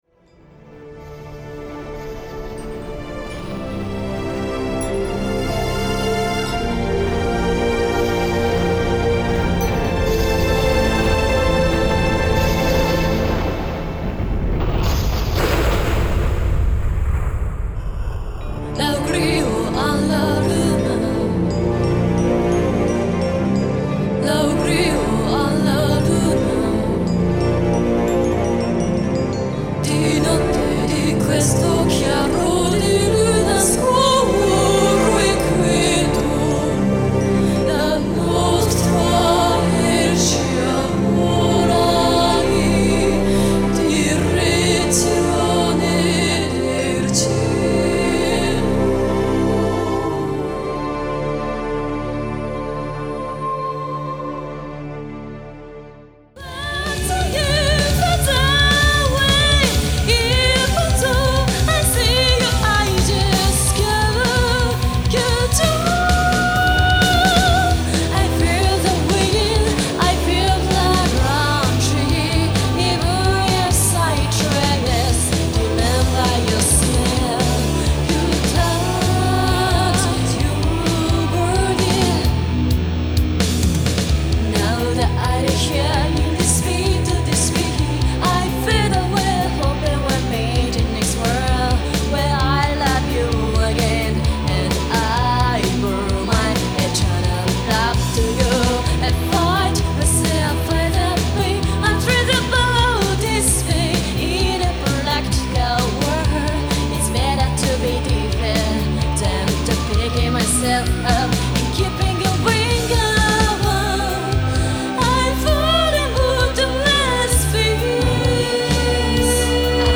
※左のプレイヤーで全曲クロスフェイドが試聴出来ます。